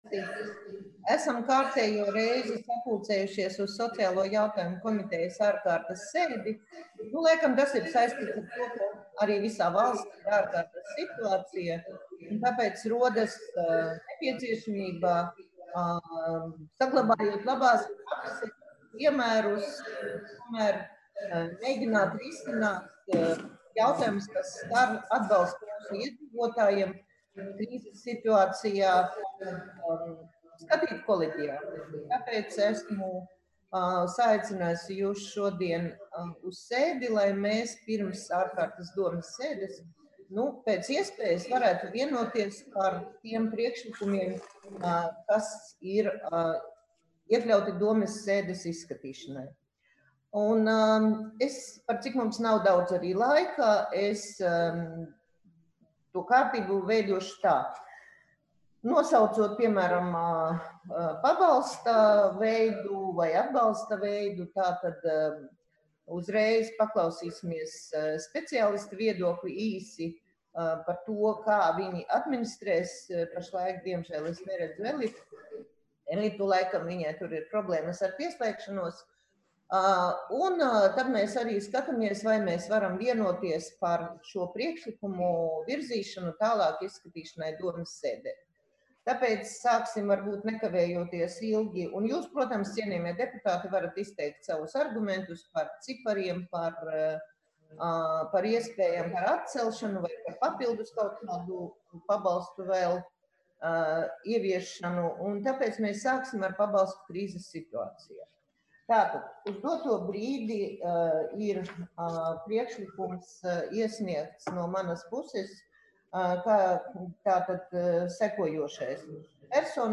Daugavpils pilsētas domes Sociālo jautājumu komitejas ārkārtas sēde darba kārtība 2020.gada 6.aprīlī, plkst.14.30 Videokonferences režīmā
Ziņotājs: Sociālo jautājumu komitejas priekšsēdētāja H.Soldatjonoka